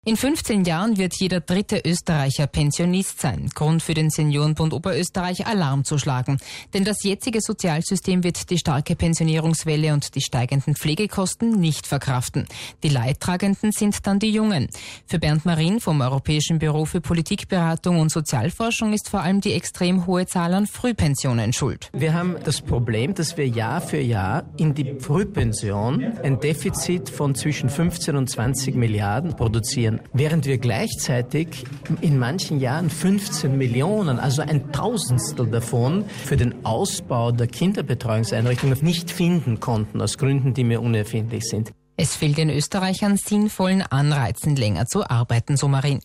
Life Radio Reportage über Pensionsantritt der Babyboomer